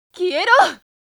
サンプルボイス＿藤原恵理
ゲームで使うキャラの１人、「藤原恵理（ふじわらえり）」のキャラクターボイス（以下「CV」）がきたことです！